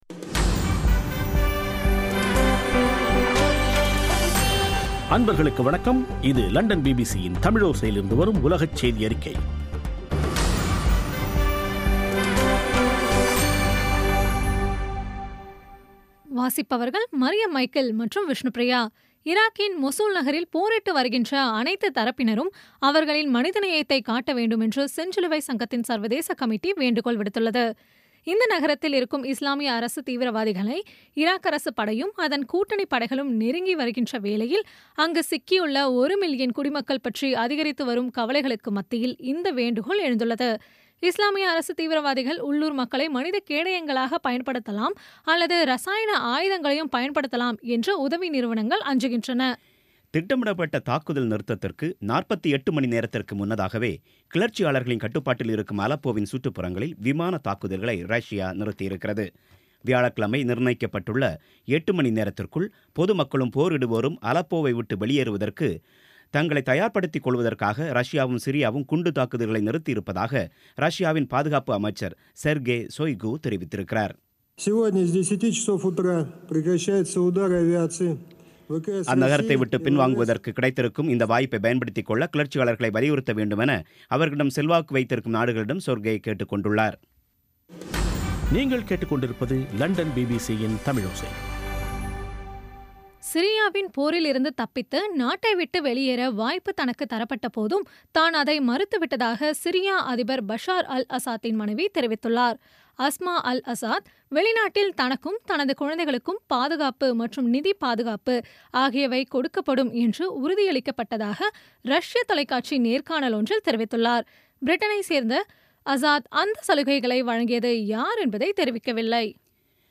இன்றைய (அக்டோபர் 18ம் தேதி) பிபிசி தமிழோசை செய்தியறிக்கை